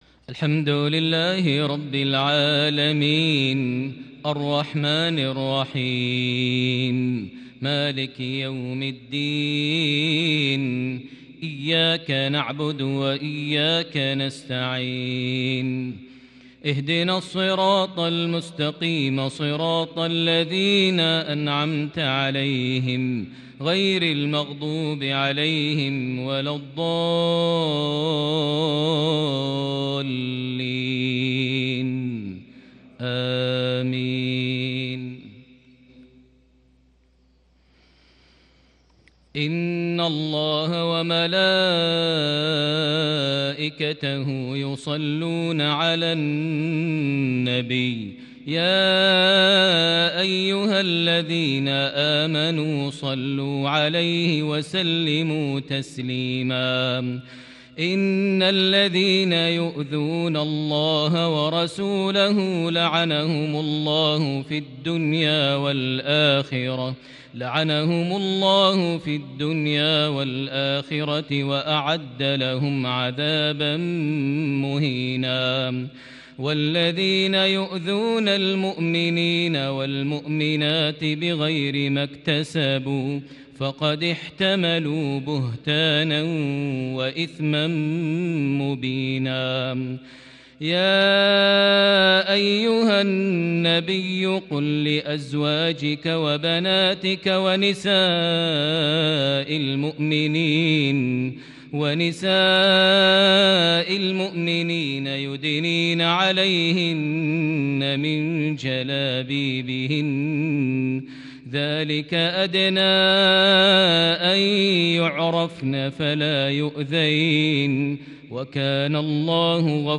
صلاة الفجر ١٧ شعبان ١٤٤١هـ خواتيم سورة الأحزاب > 1441 هـ > الفروض - تلاوات ماهر المعيقلي